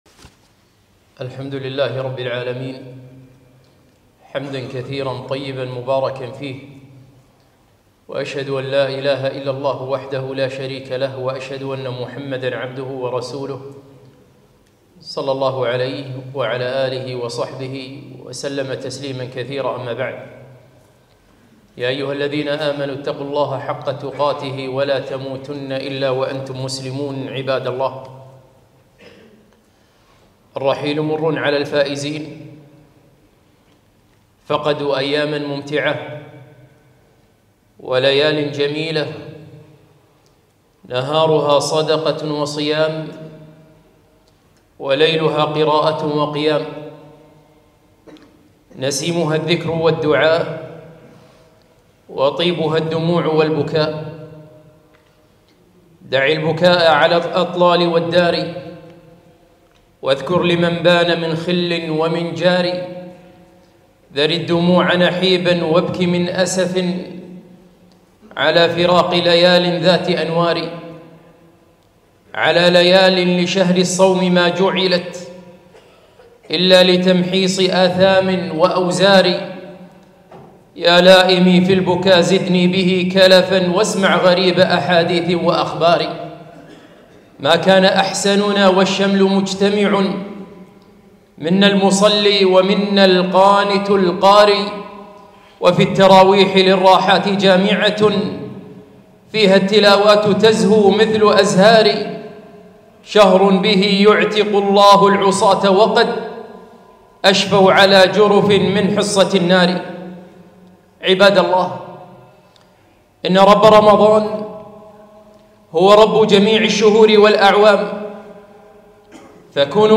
خطبة - ها قد انتهى شهر رمضان